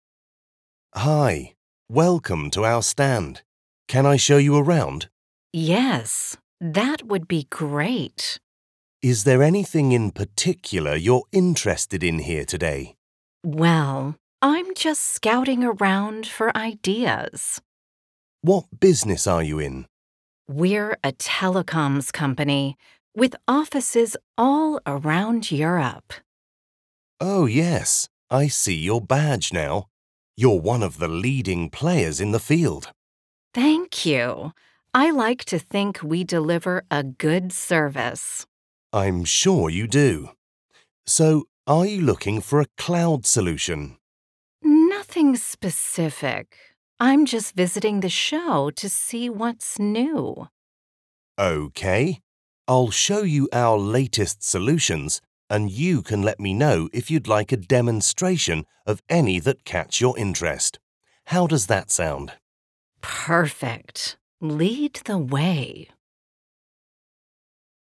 Un dialogo interattivo che mette in evidenza il vocabolario commerciale nel contesto di eventi e fiere.
Speaker (UK accent)
Speaker (American accent)